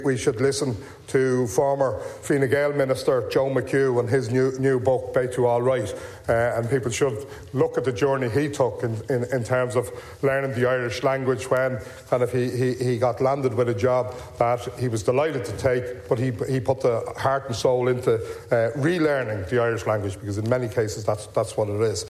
Speaking in the Dail while lobbying for votes to become the Dail’s Leas-Ceann Comhairle, Aengus O’Snodaigh hailed a former Donegal Minister for his efforts to learn the Irish language.
Deputy O’Snodaigh says others should take inspiration from the former Minister: